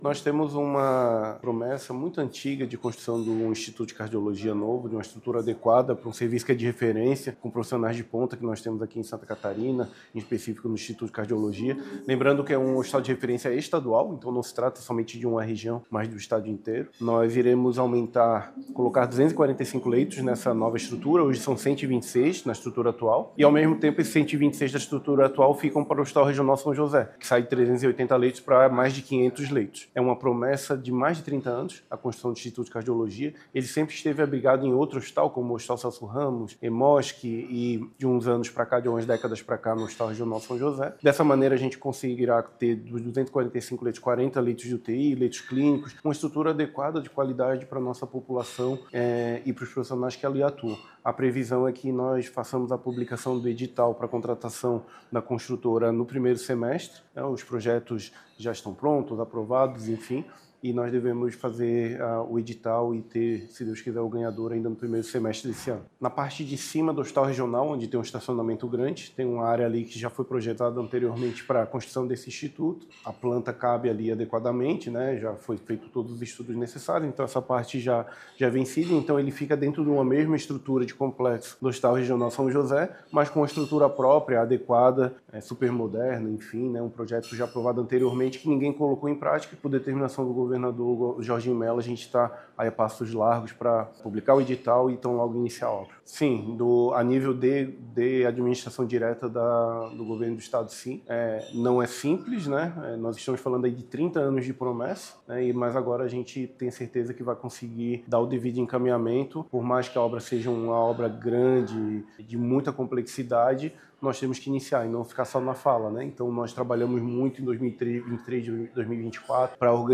O secretário Diogo Demarchi destacou que, desde 2023, uma série de esforços vêm sendo realizados para organizar a execução da obra e que a publicação do edital está prevista para o primeiro semestre deste ano: